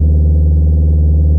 improved sfx quality
fastidle.wav